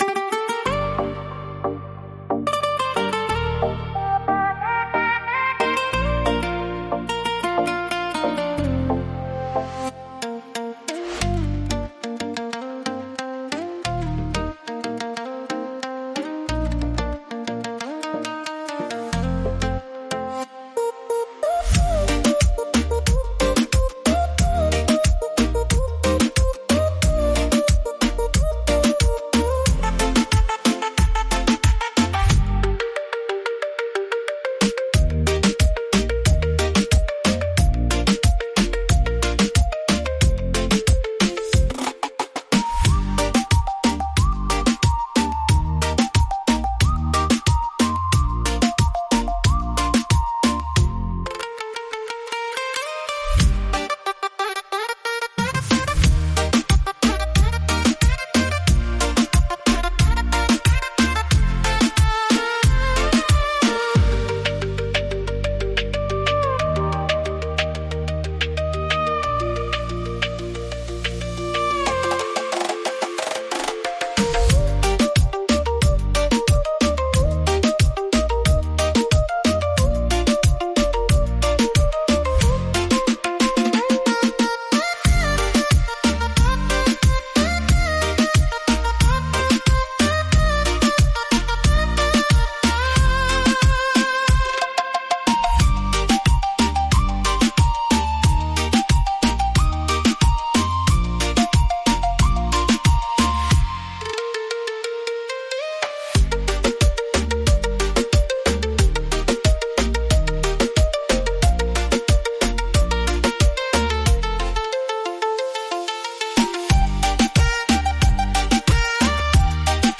Beat Reggaeton Instrumental
Produzione Reggaeton Professionale
Acapella e Cori Reggaeton Inclusi
• Mix e mastering di qualità studio
A#